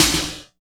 50.08 SNR.wav